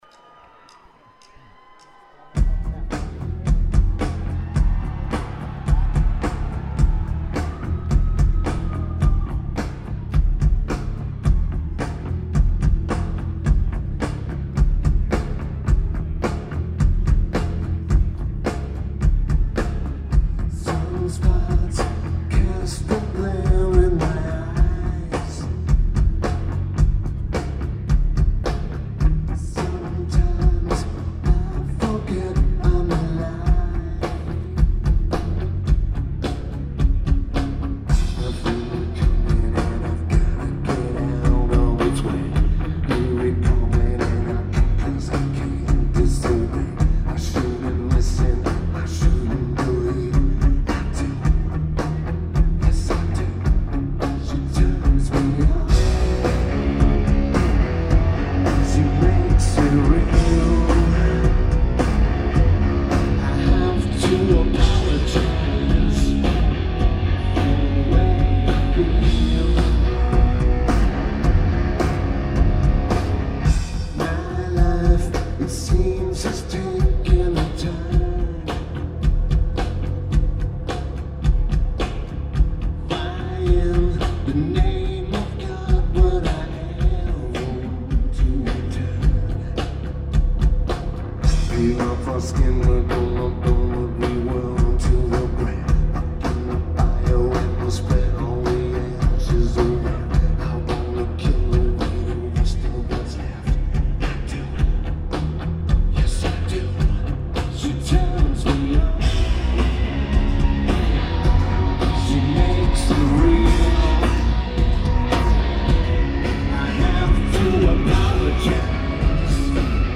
Lineage: Audio - AUD (SP-CMC-4u + Sony PCM-A10)